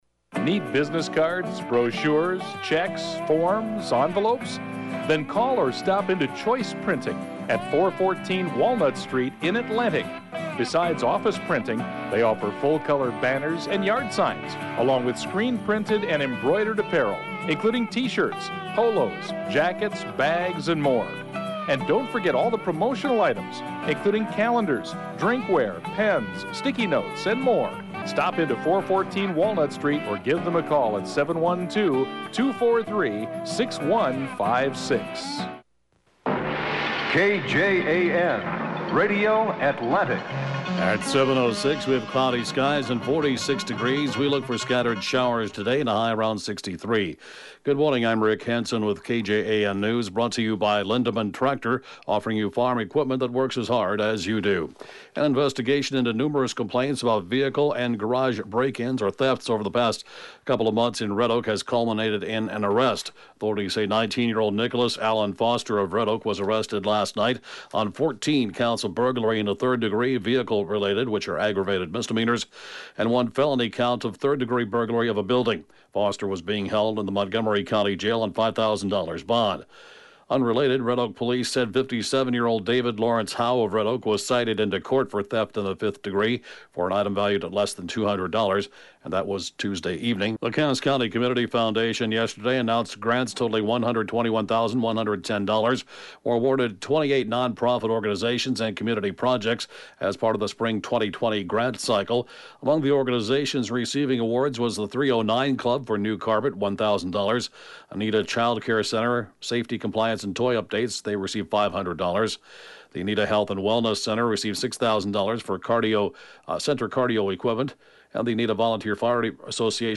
7AM Newscast 11/24/2018